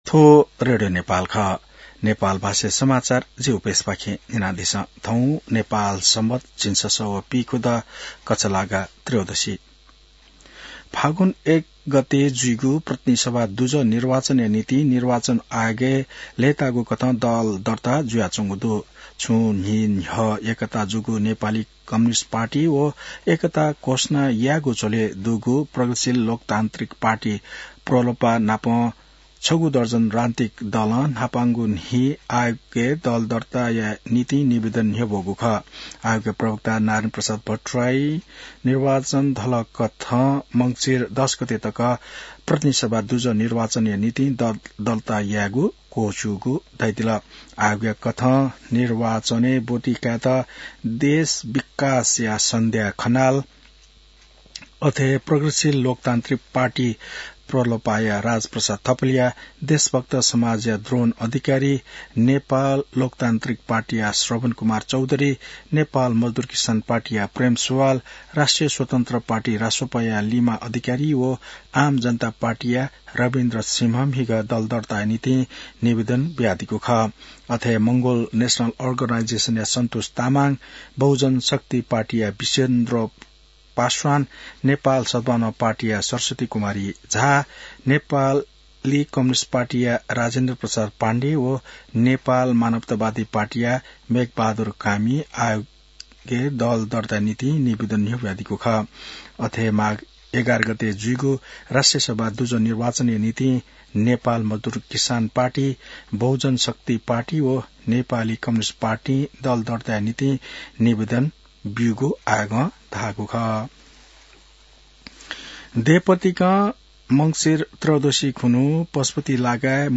An online outlet of Nepal's national radio broadcaster
नेपाल भाषामा समाचार : २ मंसिर , २०८२